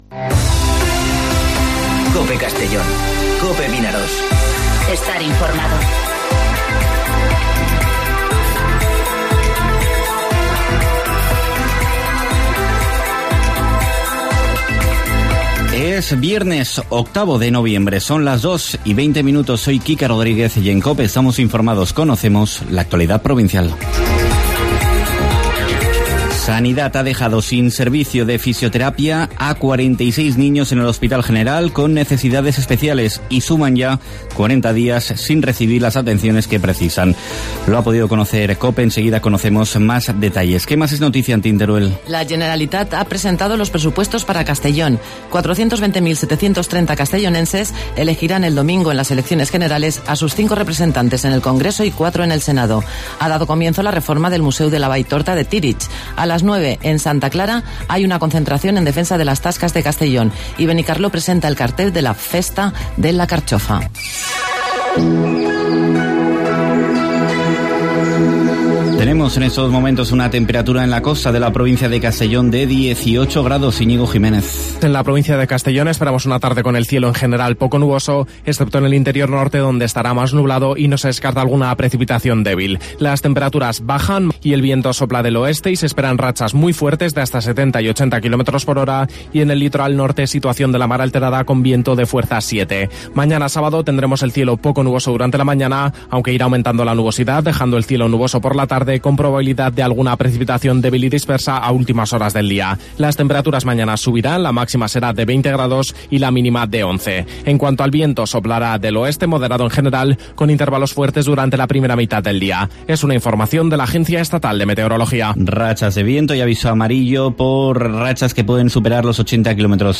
Informativo Mediodía COPE en Castellón (08/11/2019)